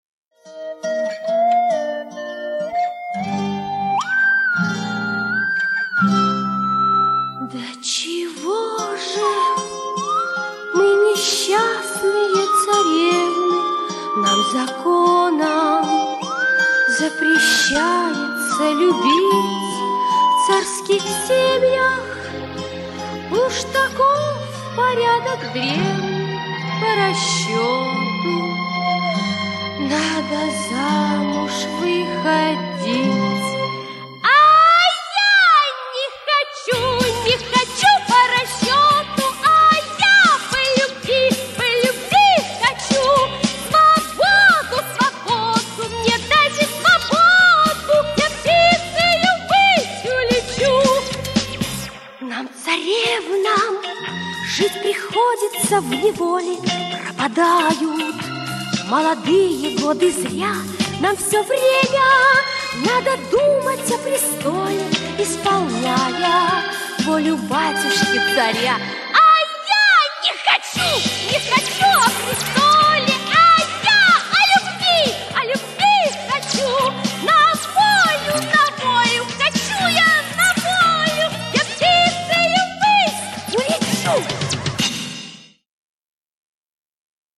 Категория: Детские песенки